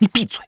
emo_hate.wav